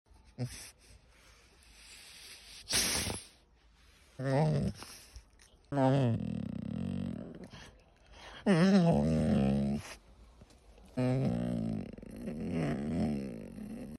The Sounds Of A Whippet Sound Effects Free Download